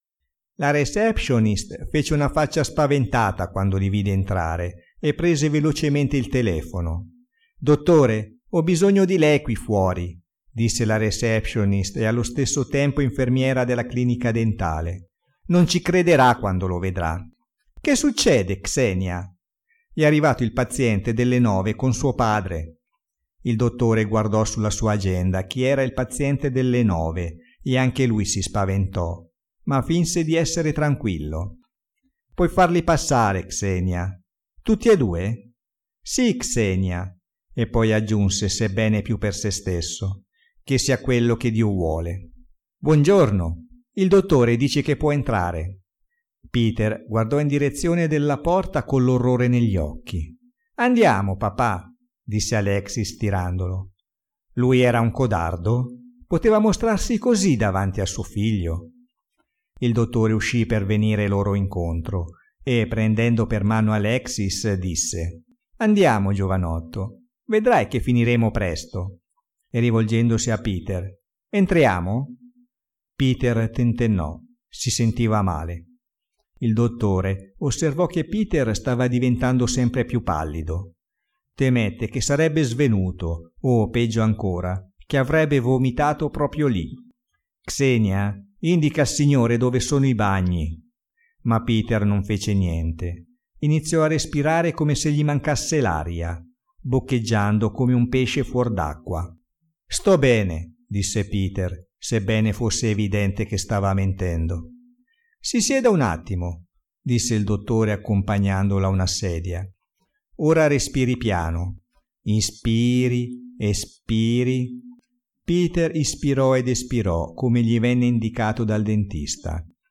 Scarica i primi capitoli in audiolibro